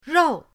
rou4.mp3